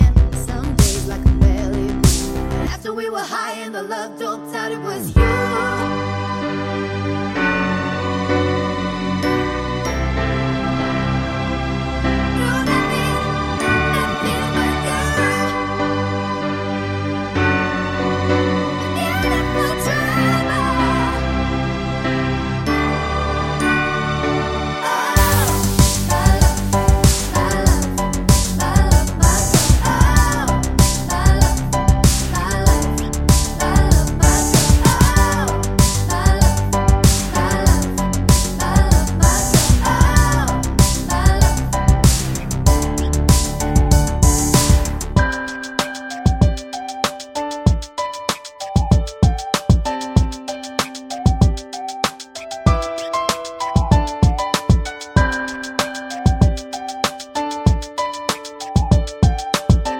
With Clean Backing Vocals Rock 3:55 Buy £1.50